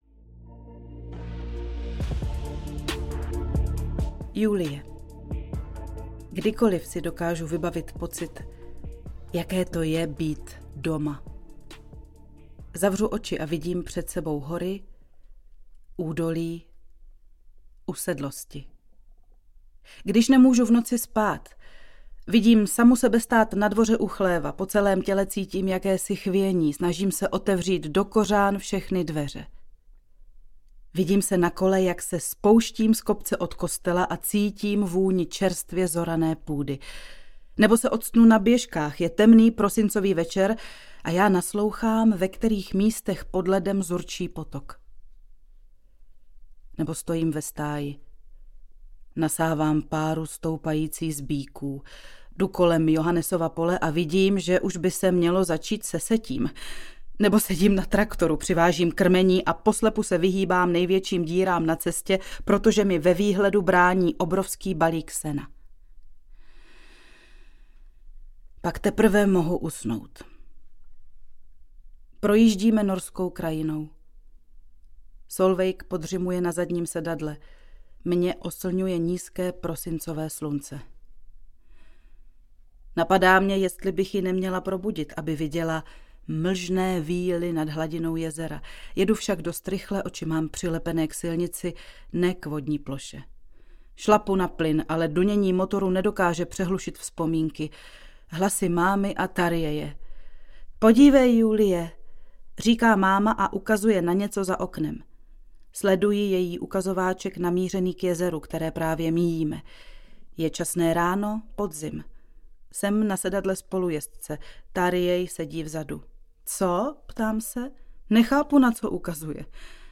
Každý chce domu, nikdo zpátky audiokniha
Ukázka z knihy